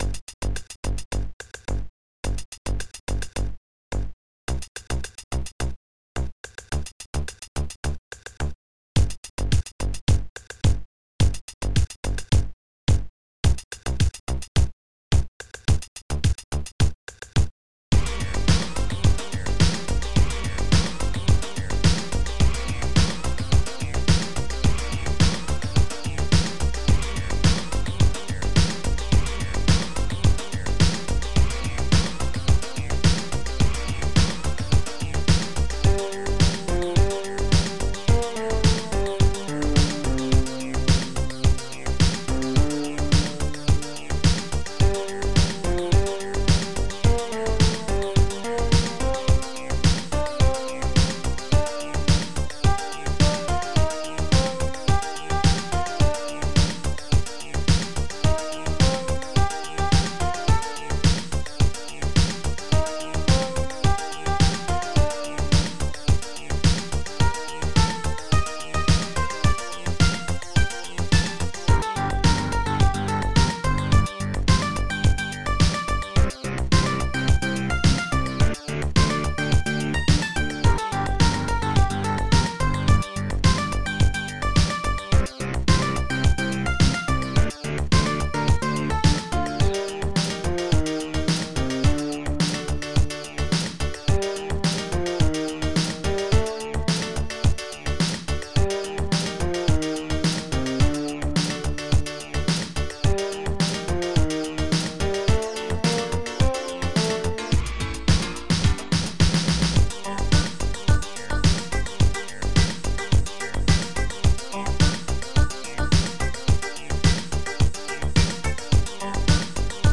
st-05:xhousedrum
st-07:APIANO
st-08:..woodblock